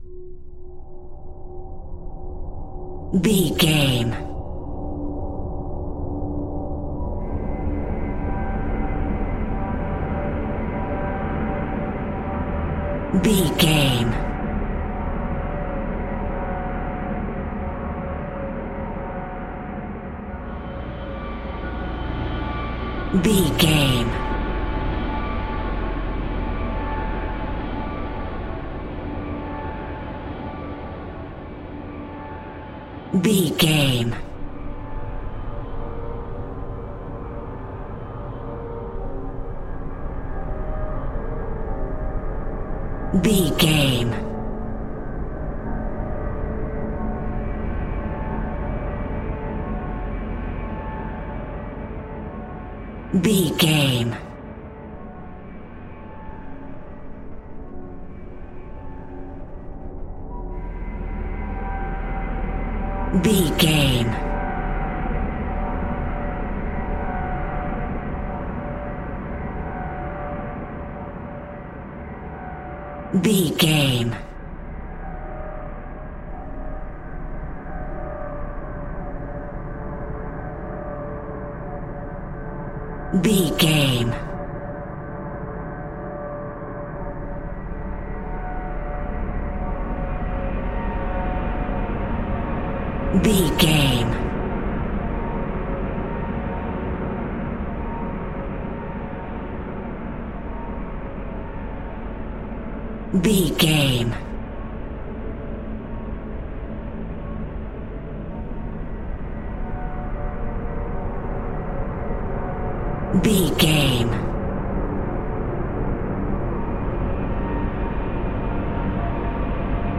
Atonal
Slow
ominous
eerie
Horror Ambience
dark ambience
Synth Pads
Synth Ambience